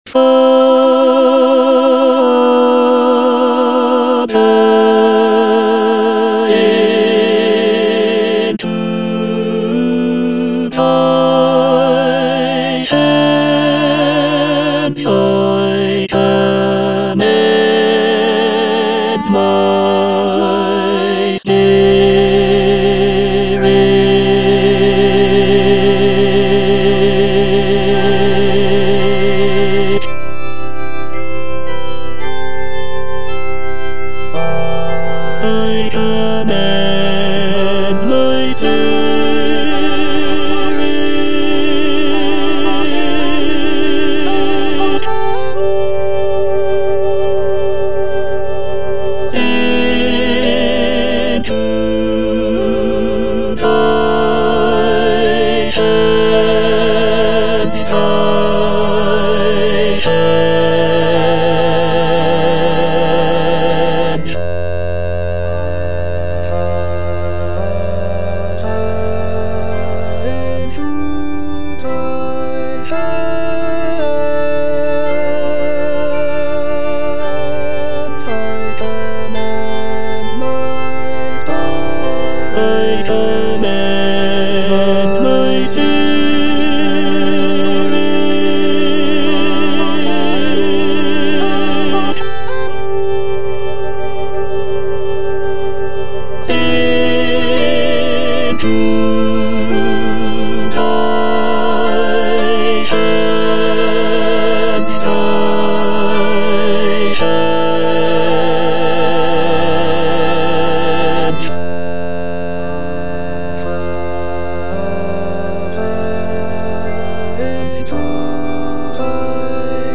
(tenor II) (Emphasised voice and other voices) Ads stop